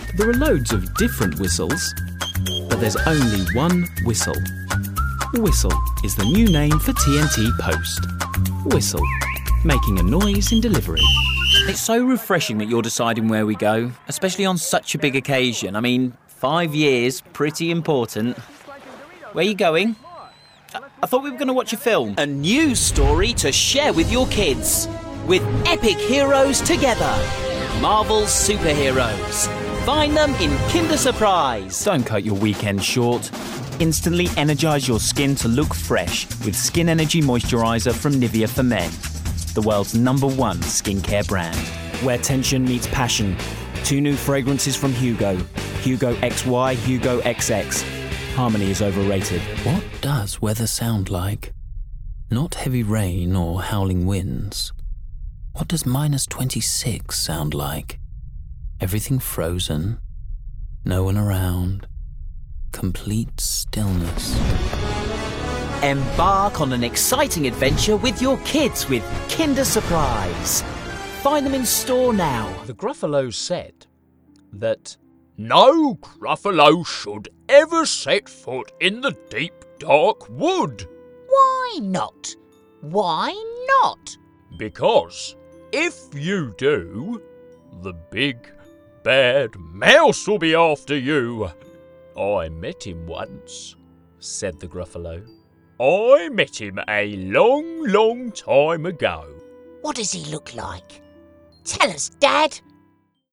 Commercial Showreel
London, RP ('Received Pronunciation'), Straight, Various